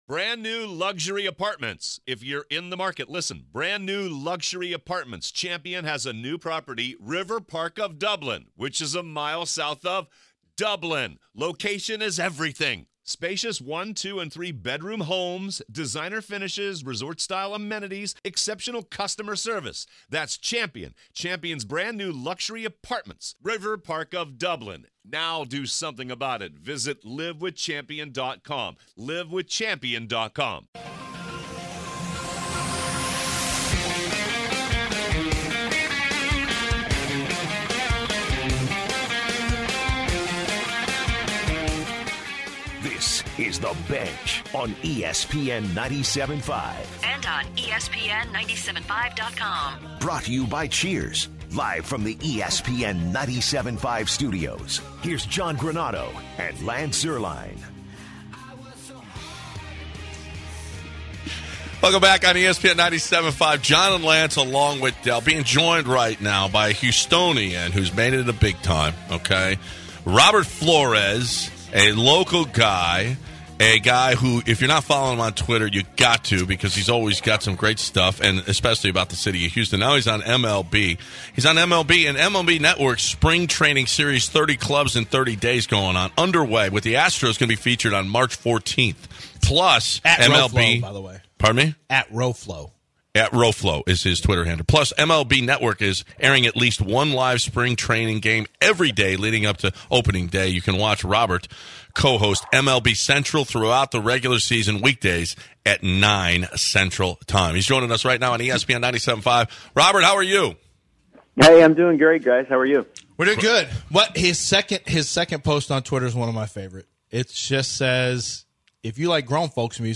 02/26/2019 MLB Network’s Robert Flores joins The Bench